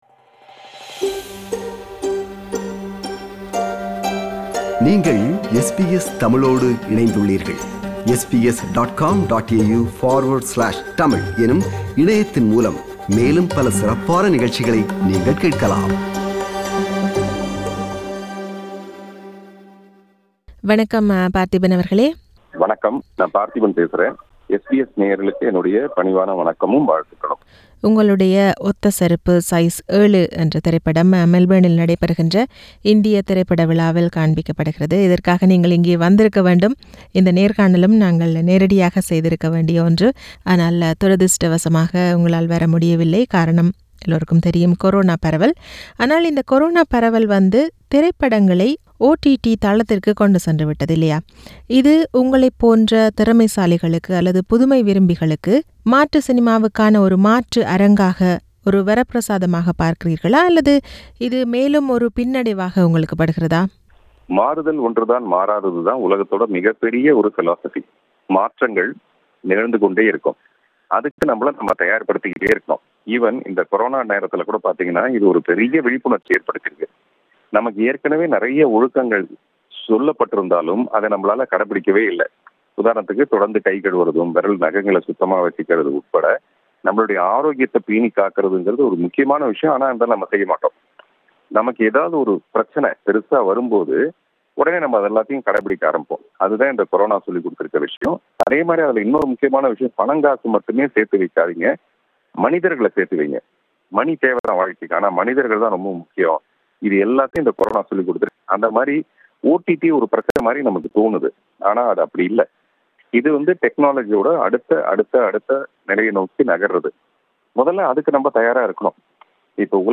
இயக்குனர், நடிகர், தயாரிப்பாளர் என பன்முகம் கொண்ட இராதாகிருஷ்ணன் பார்த்திபன் அவர்களது ஒத்த செருப்பு சைஸ் 7 திரைப்படம் மெல்பேர்ன் இந்திய திரைப்பட விழாவில் காண்பிக்கப்படுகிறது. இது தொடர்பில் பார்த்திபன் அவர்களோடு உரையாடுகிறார்